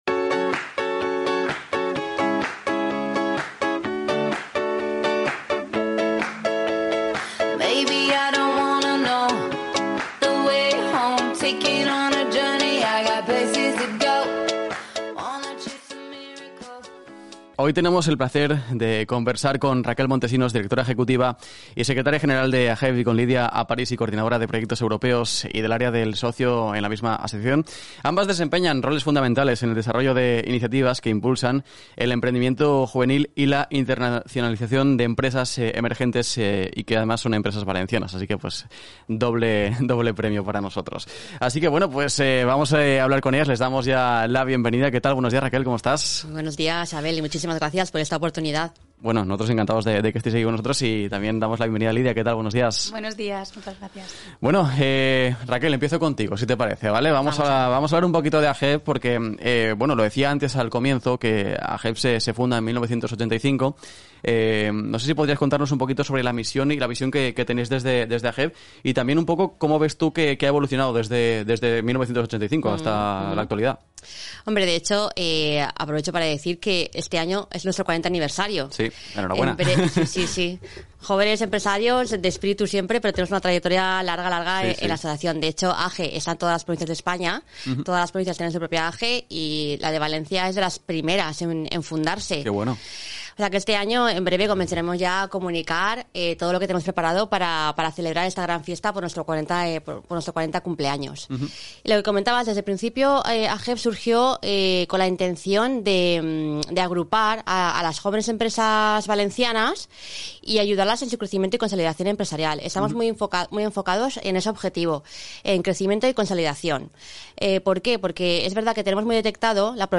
Entrevista al área de Erasmus AJEV – AJEV